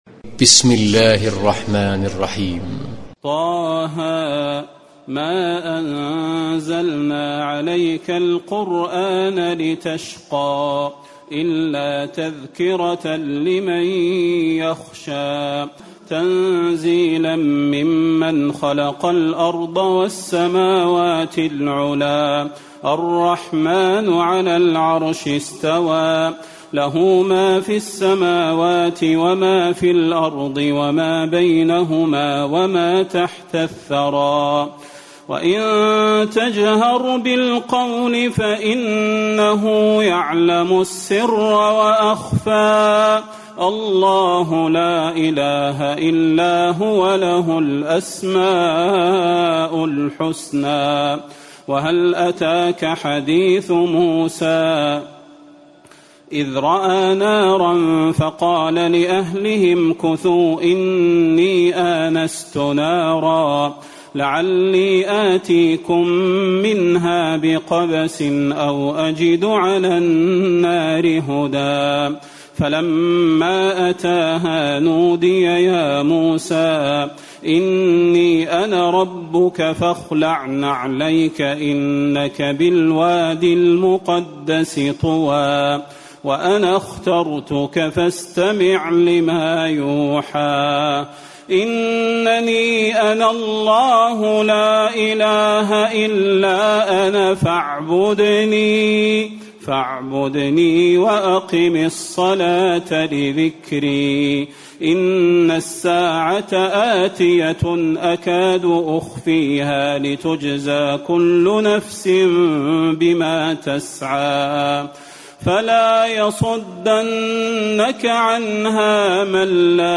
تراويح الليلة الخامسة عشر رمضان 1437هـ سورة طه كاملة Taraweeh 15 st night Ramadan 1437H from Surah Taa-Haa > تراويح الحرم النبوي عام 1437 🕌 > التراويح - تلاوات الحرمين